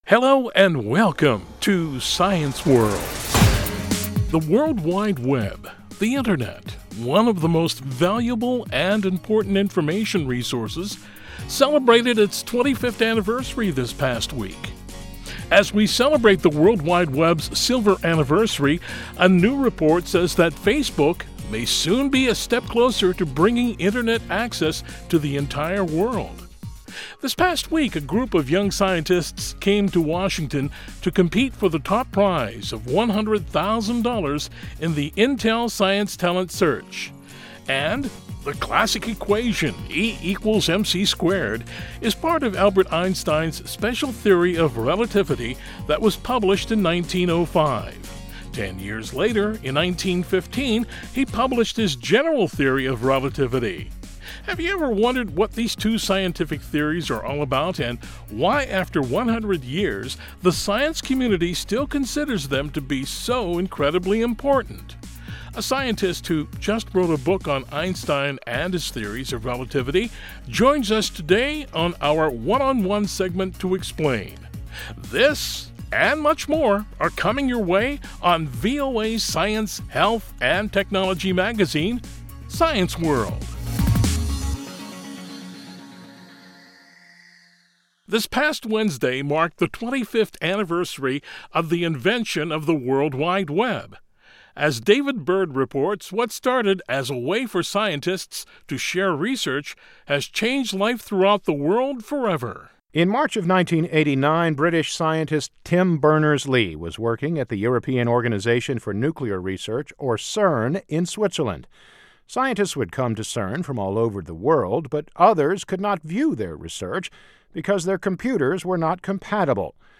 Have you ever wondered what these two scientific theories are all about and why after 100 years the science community still considers them to be so incredibly important. A scientist who just wrote a book on Einstein and his theories of relativity joins us today on our One on One Segment to explain.